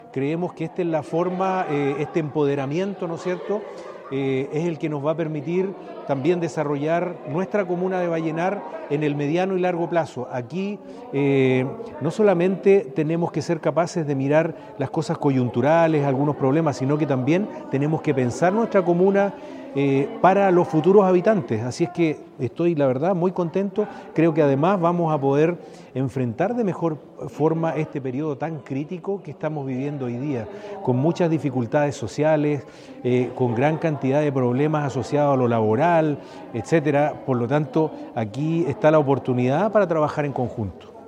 Armando Flores Jiménez, alcalde la comuna, señaló su satisfacción por el éxito alcanzado mediante esta iniciativa, indicando que “para nosotros es muy importante esta gestión que se está haciendo, porque desde el municipio siempre hemos pensado que es necesario que nuestro dirigentes y dirigentes se empoderen totalmente de su rol”
Cuna-Alcalde-1.mp3